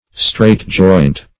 Straight-joint \Straight"-joint`\, a. (Arch.)